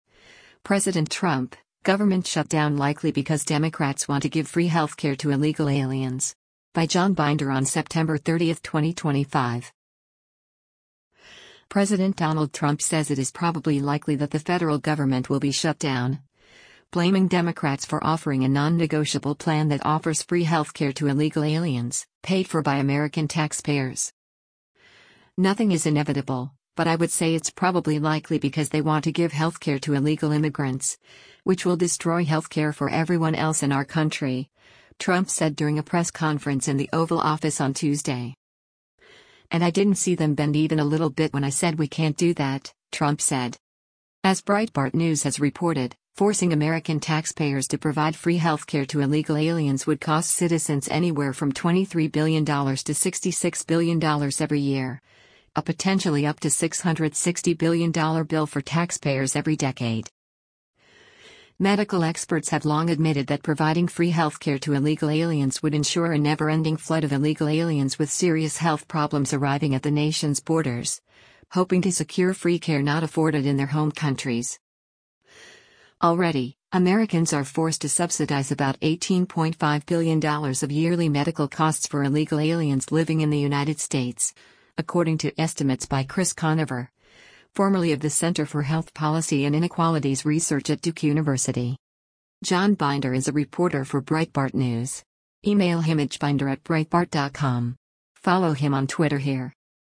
“Nothing is inevitable, but I would say it’s probably likely because they want to give health care to illegal immigrants, which will destroy health care for everyone else in our country,” Trump said during a press conference in the Oval Office on Tuesday.